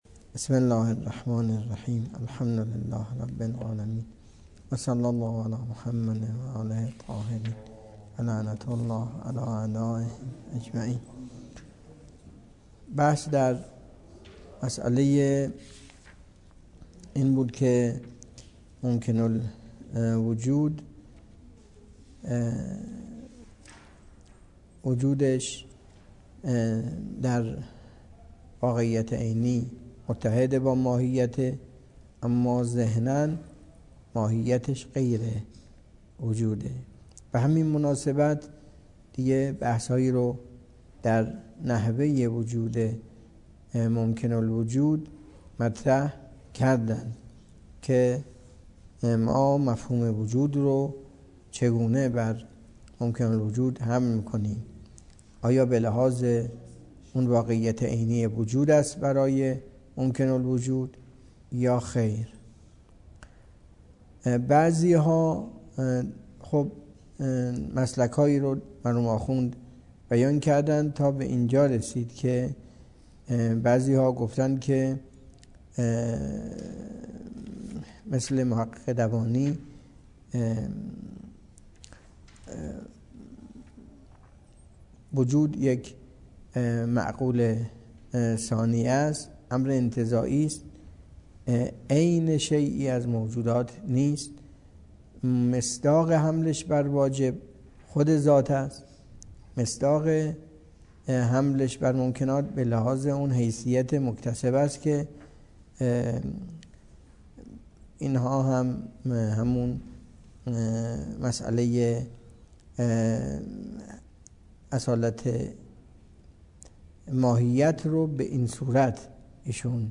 درس فلسفه اسفار اربعه